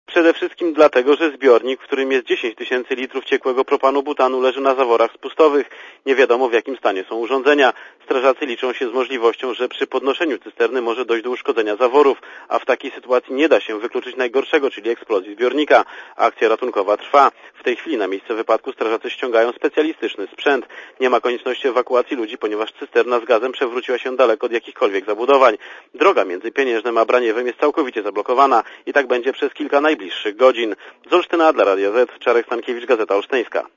(RadioZet) Źródło: (RadioZet) Posłuchaj relacji (131 KB)